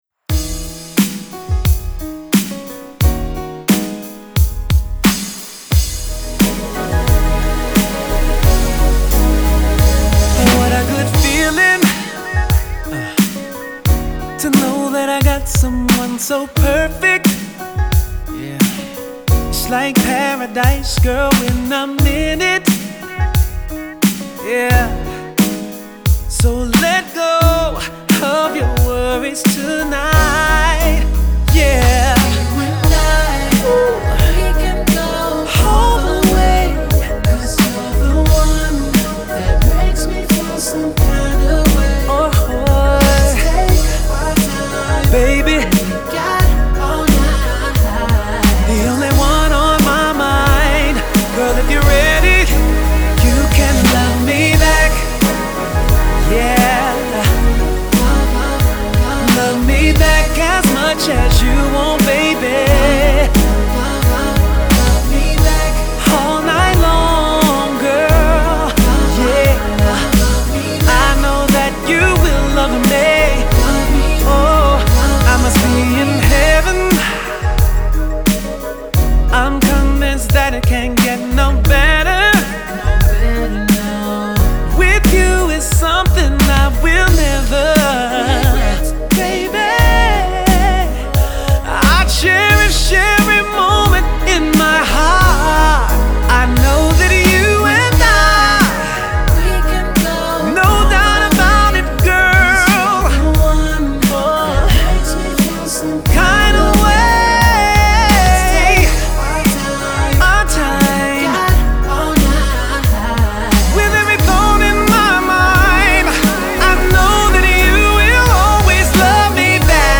R&B crooner